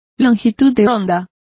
Complete with pronunciation of the translation of wavelength.